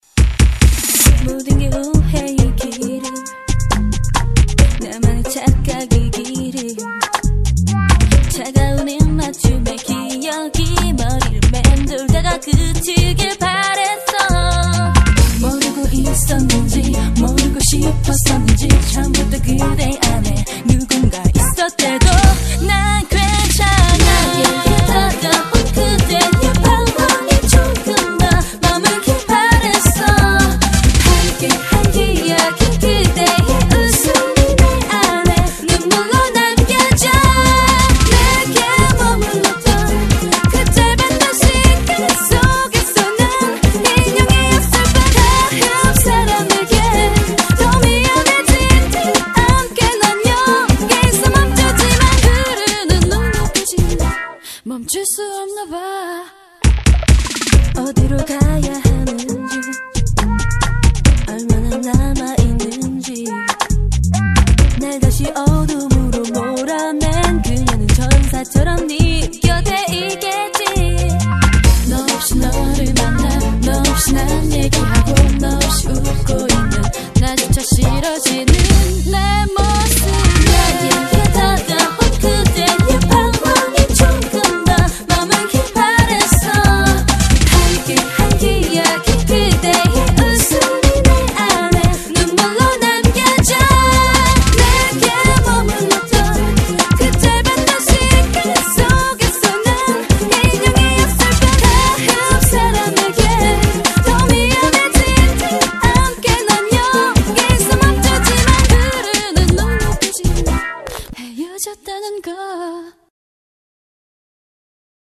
BPM136--1
Audio QualityPerfect (High Quality)